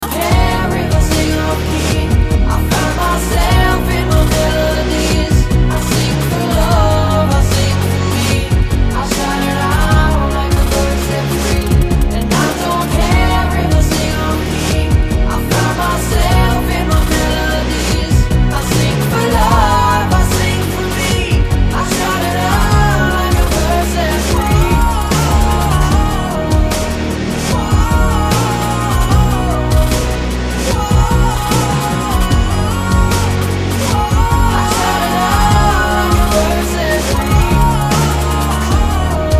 • Качество: 256, Stereo
поп
мужской вокал
громкие
Cover
романтичные